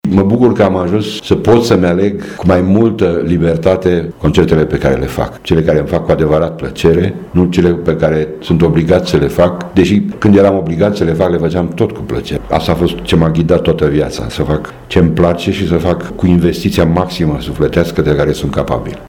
L-am întrebat pe Dan Grigore ce gânduri îl animă, la împlinirea celor 60 de ani de activitate artistică